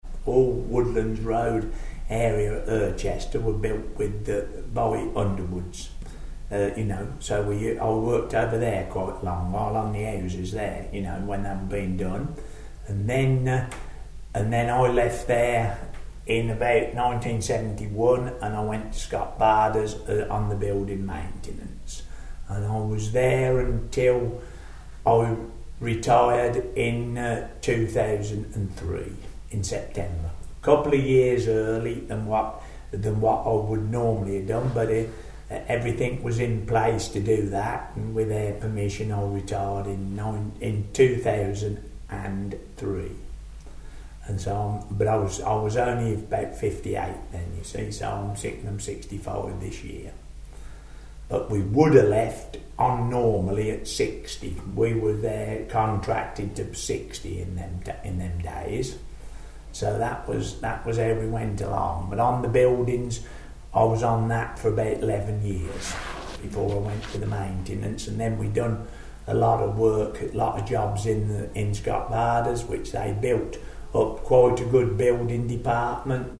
From an interview